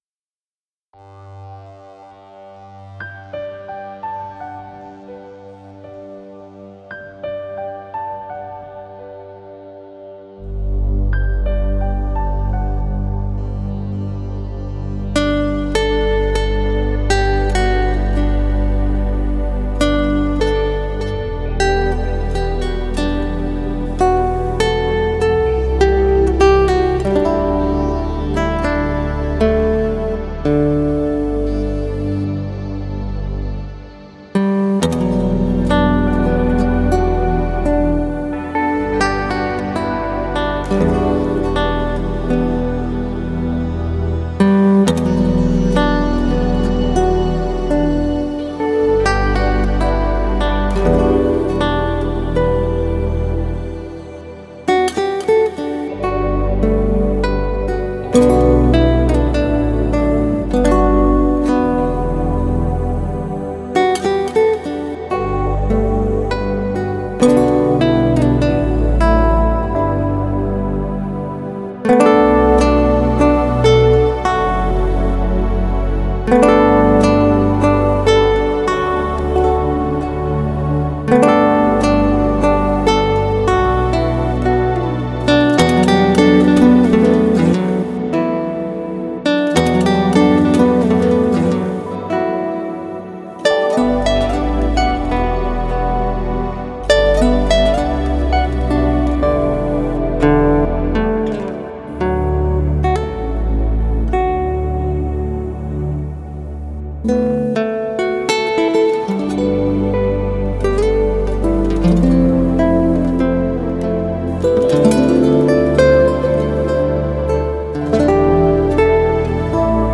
Набросали с гитаристом такую вот миниатюру Если что, по-вашему, не так - рад рассмотреть и принять к сведению.